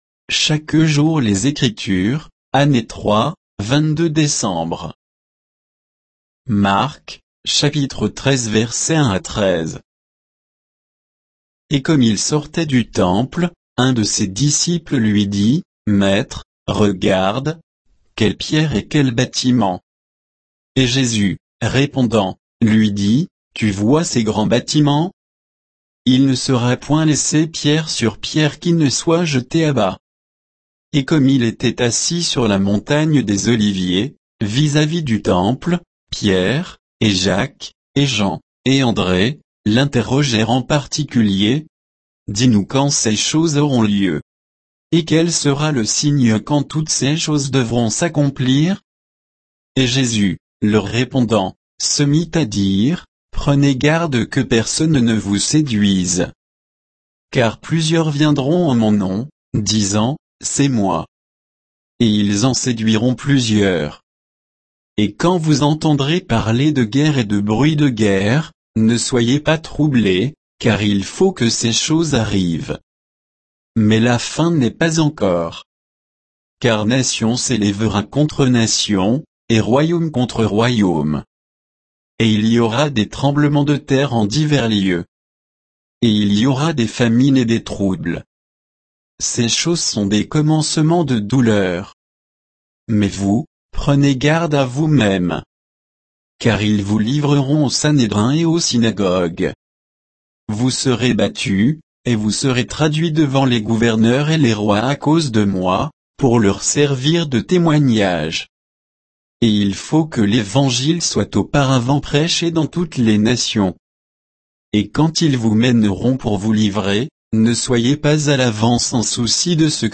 Méditation quoditienne de Chaque jour les Écritures sur Marc 13, 1 à 13